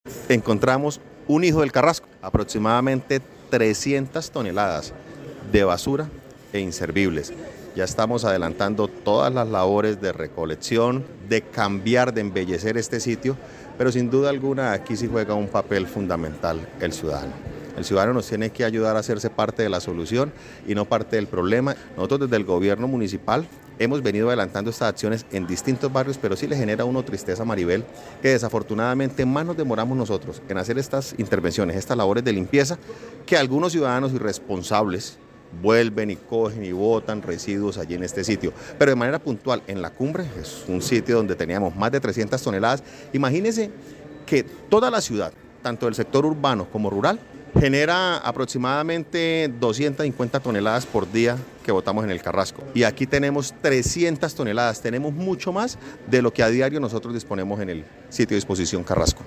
José Fernando Sánchez, alcalde de Floridablanca